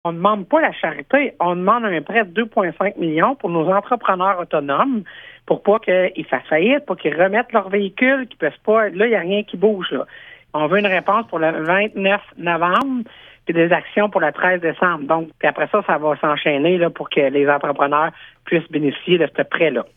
Les maires de la Vallée-de-la-Gatineau attendent une décision d’ici vendredi, comme l’indique la préfète, Chantal Lamarche :